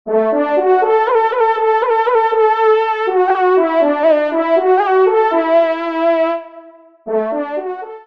FANFARE
Extrait de l’audio « Ton de Vènerie »
Pupitre de Chant